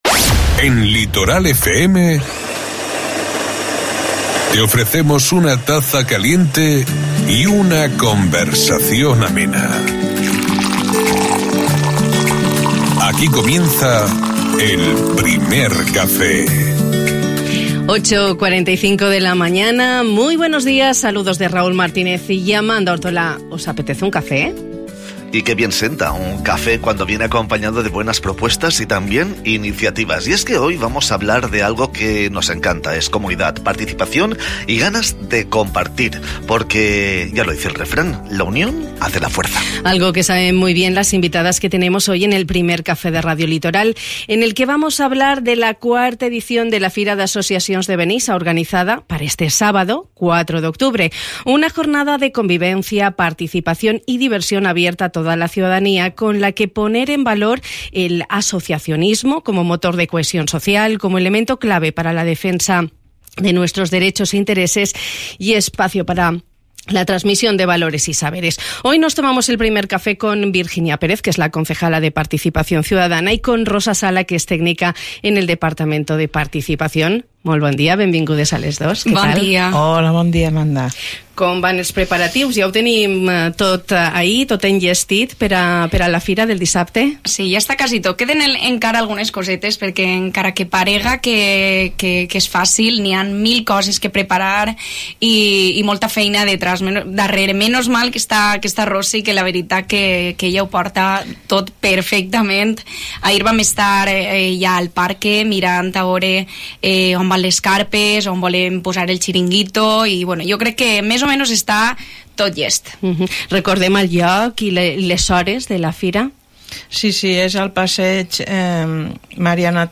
Algo que saben muy bien las invitadas que hemos tenido hoy en el Primer Café de Radio Litoral, un espacio radiofónico que hemos dedicado a la IV edición de la Fira d’Associacions de Benissa, organizada para este sábado, 4 de octubre.